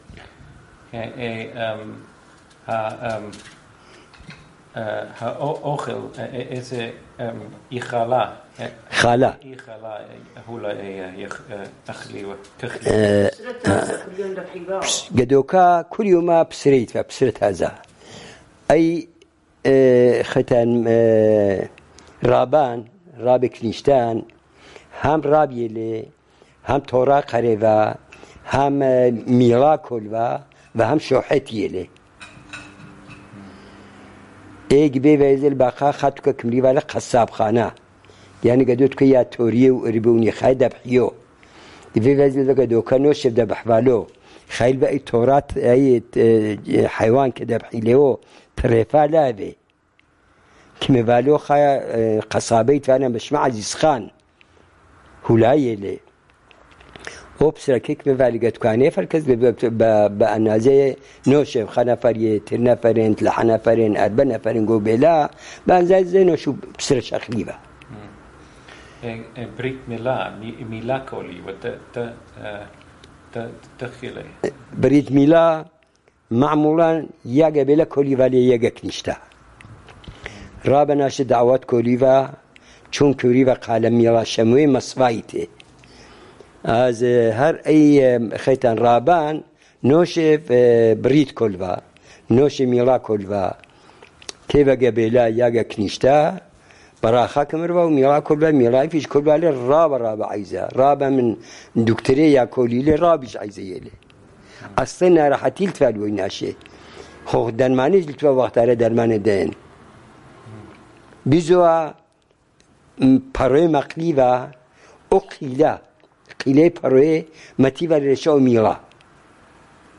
Sanandaj, Jewish: Rabbis